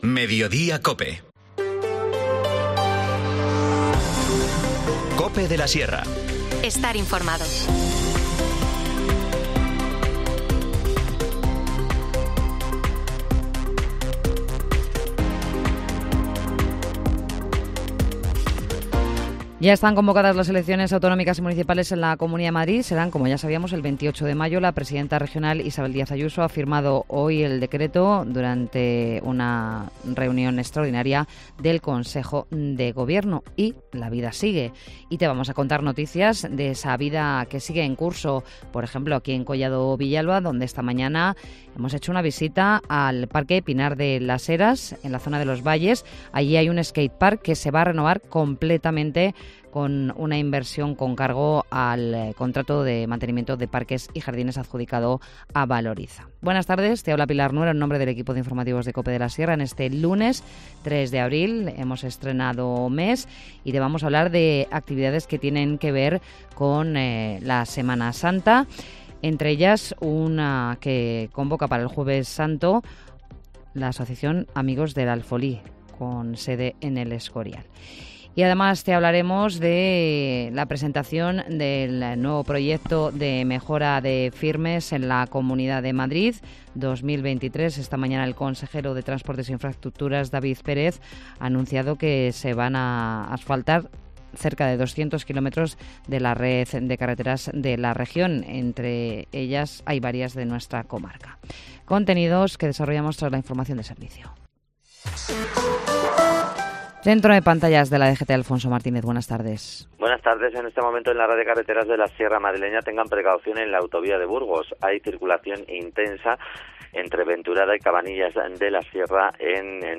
Informativo Mediodía 3 abril